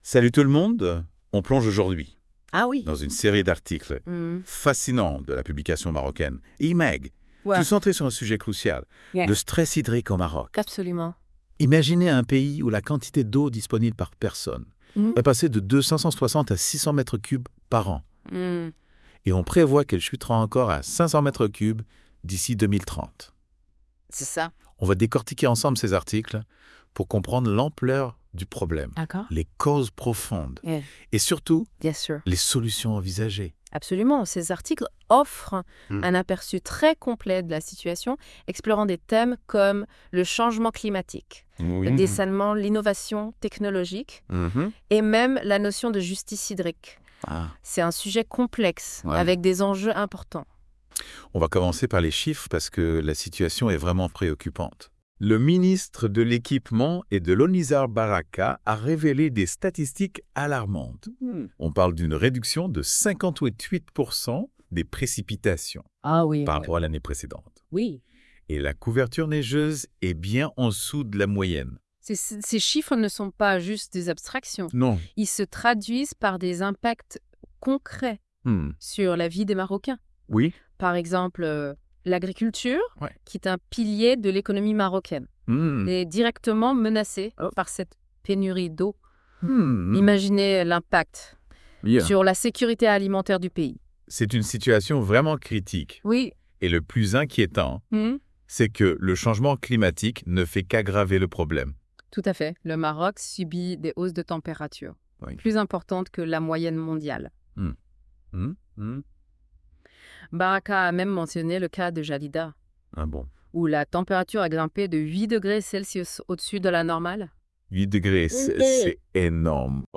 Les chroniqueurs de la Web Radio R212 ont lus attentivement ce I-MAG Spécial Stress Hydrique de L'ODJ Média et ils en ont débattu dans ce podcast